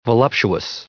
added pronounciation and merriam webster audio
1954_voluptuous.ogg